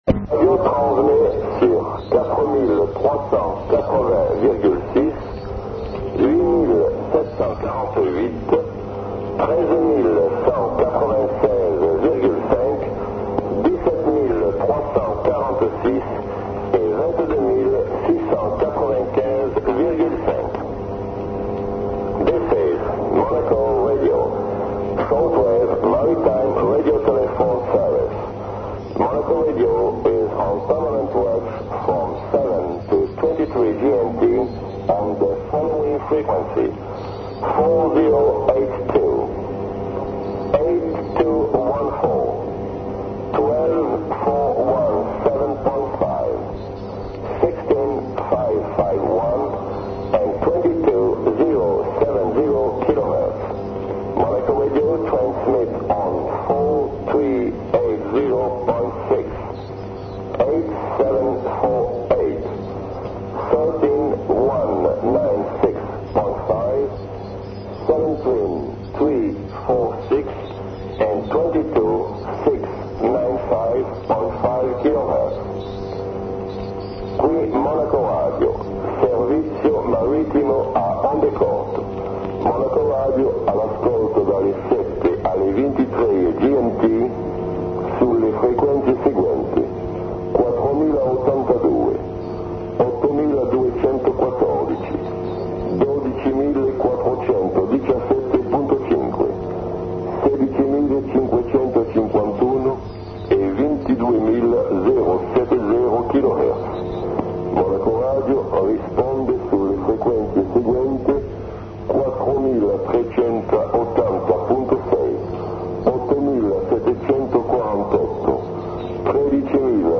a detailful transmission